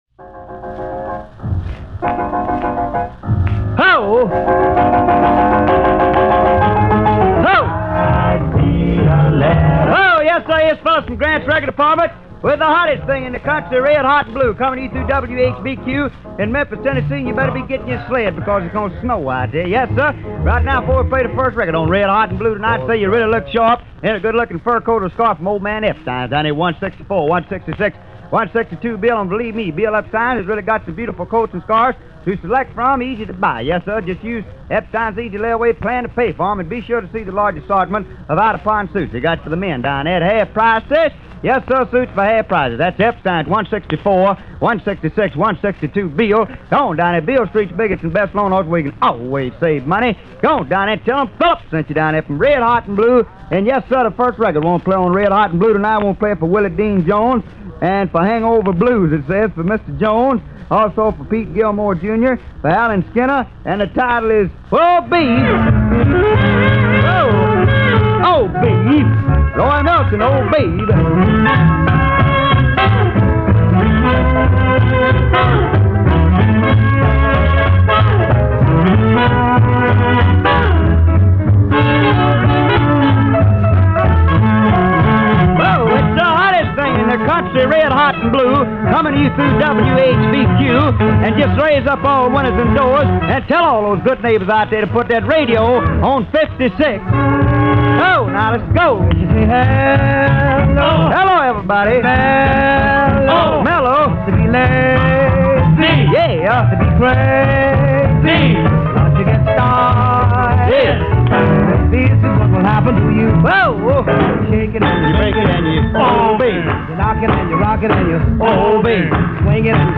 The original 16″ transcriptions of this show were not in the best shape when we got them about 30 years ago. But we’ve luckily been able to preserve the excitement and integrity of what these shows were all about.